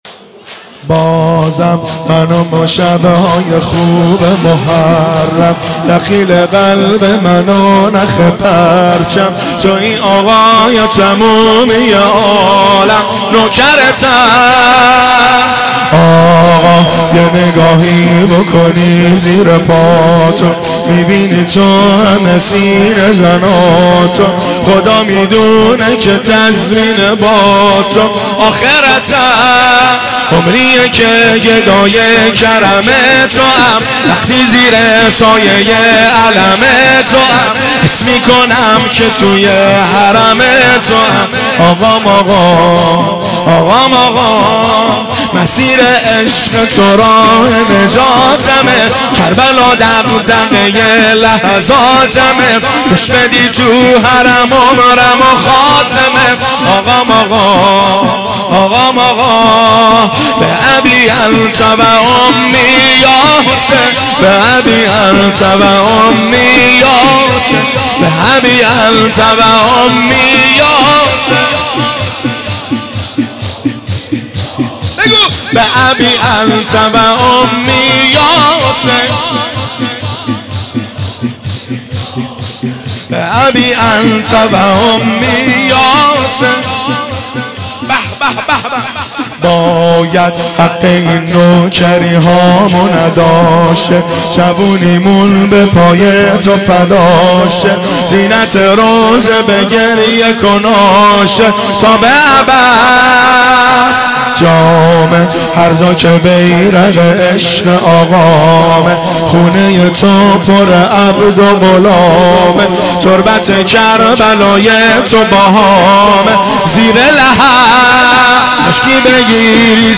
مراسم شب ششم محرم